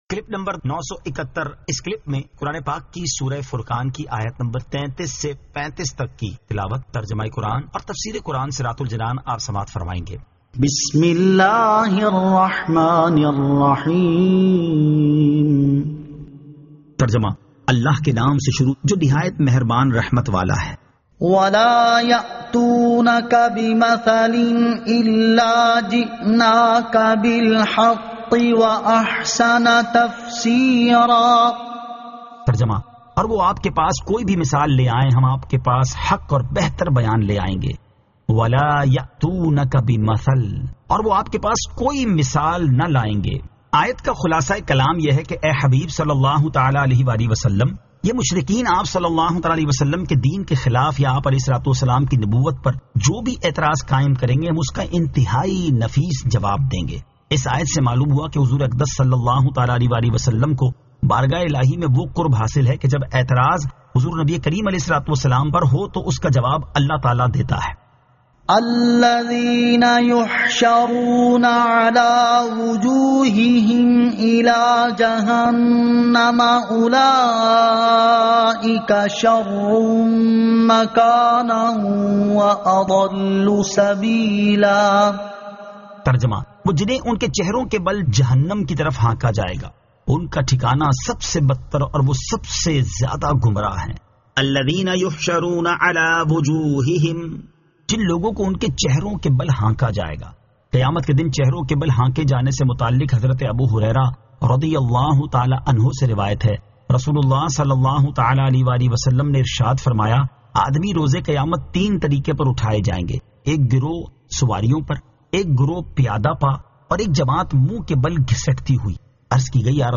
Surah Al-Furqan 33 To 35 Tilawat , Tarjama , Tafseer